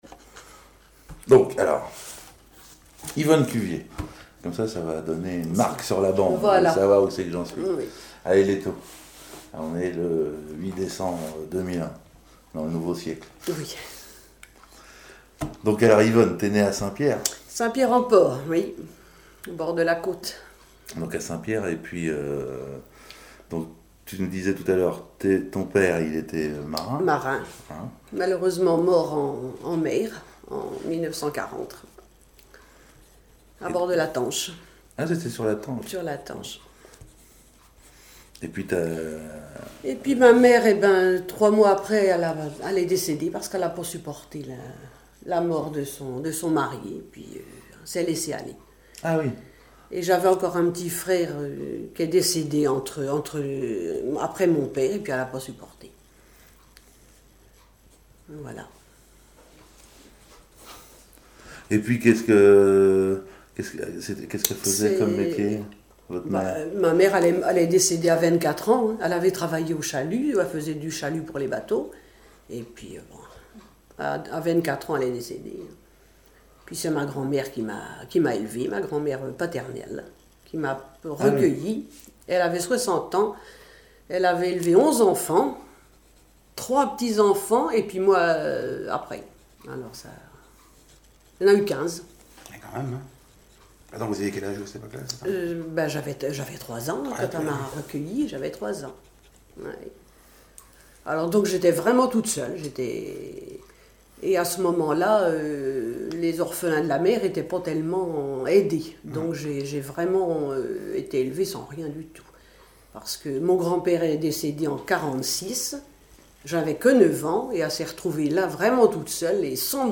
Localisation Saint-Pierre-en-Port
Catégorie Témoignage